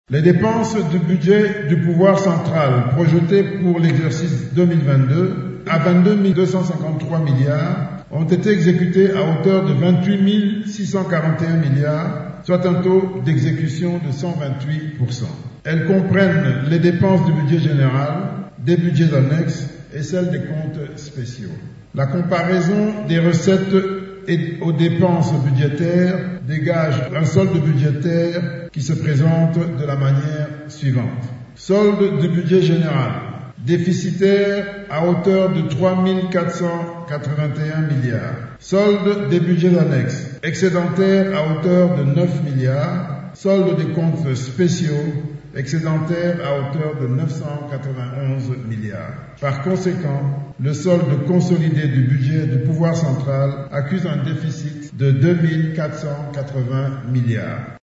Le ministre des Finances, Nicolas Kazadi a présenté, mercredi 27 septembre, à l’Assemblée nationale, l’économie du projet de loi portant reddition des comptes de l’exercice 2022.
Vous pouvez écouter ici le ministre Nicolas Kazadi :